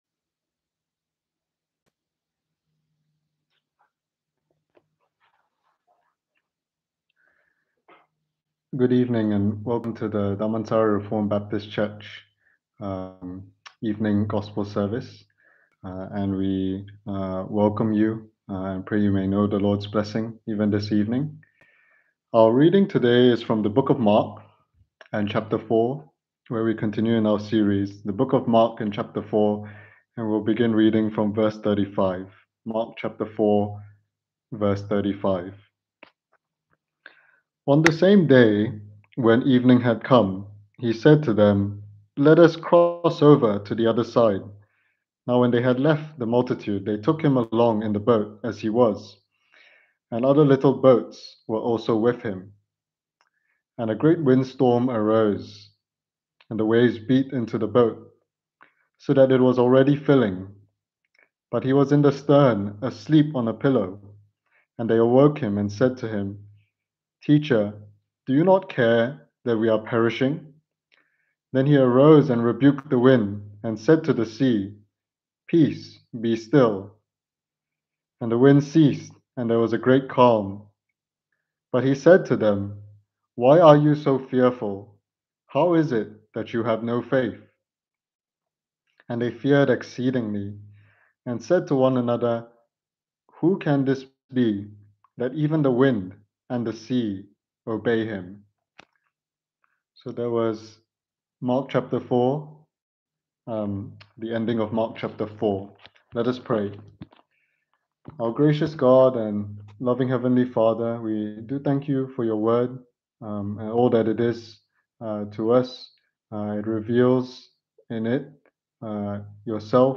delivered online for the Sunday Evening Service